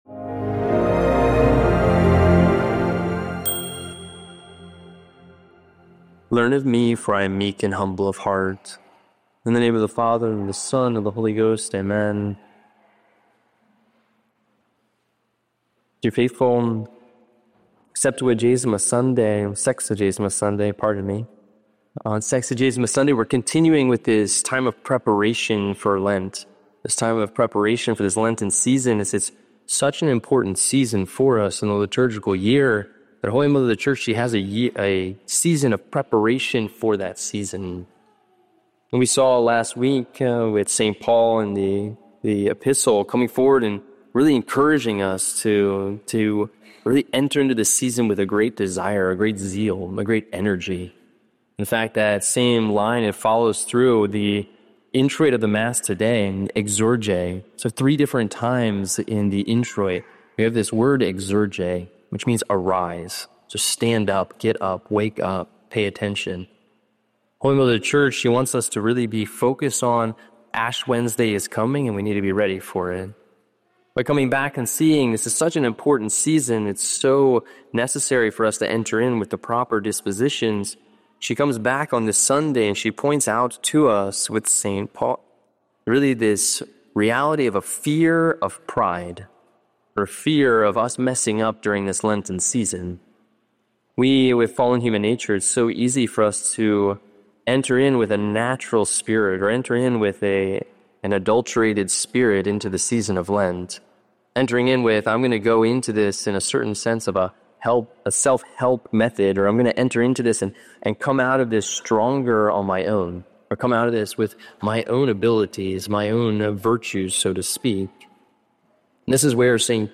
Christ's Power to Heal - SSPX Sermons